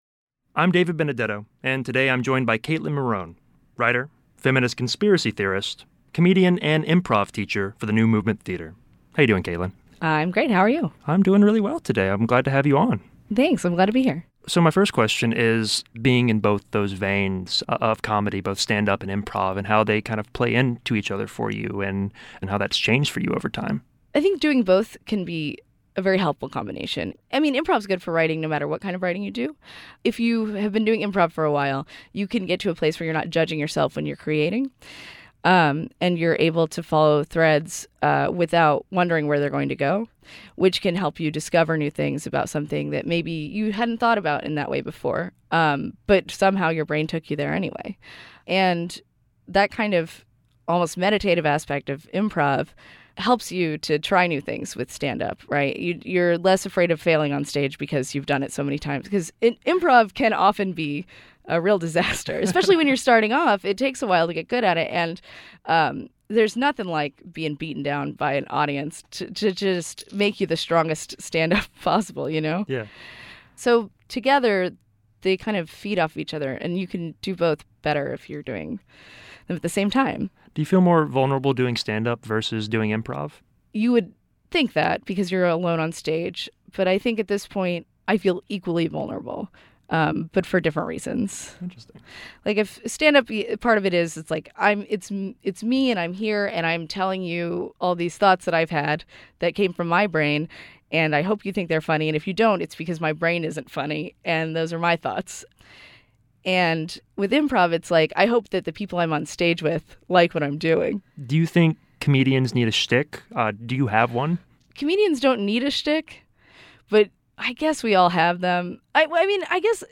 Sep 08, 2016 Audio and Podcasts, People, Performer